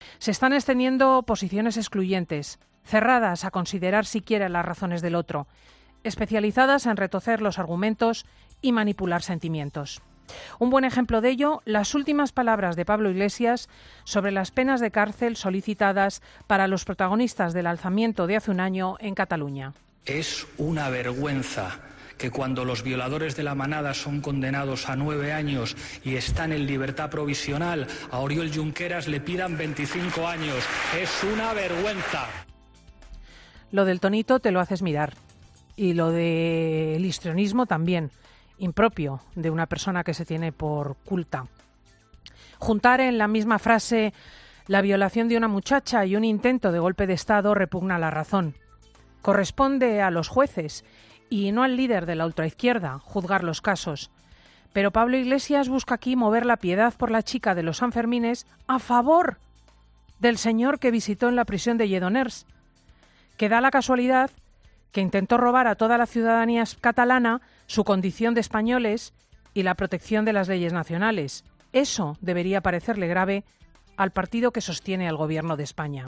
La directora de 'Fin de Semana', Cristina López Schlichting, ha respondido así al mensaje del líder de Podemos: